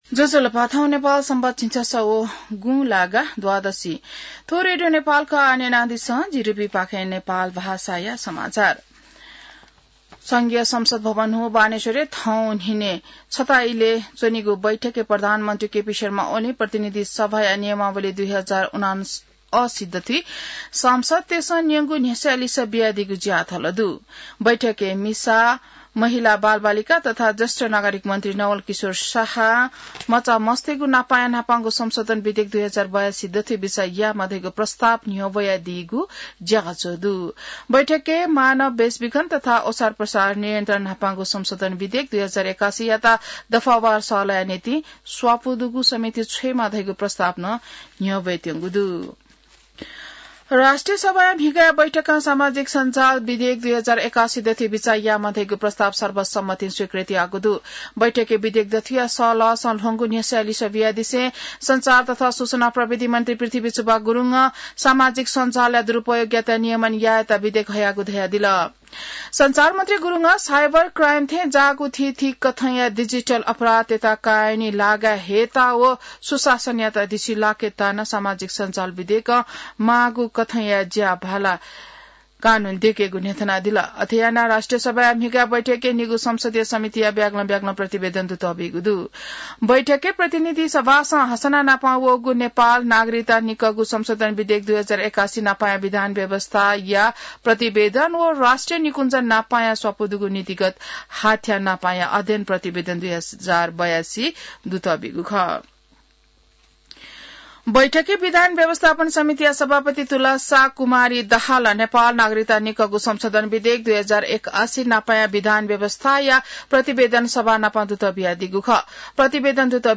नेपाल भाषामा समाचार : ४ भदौ , २०८२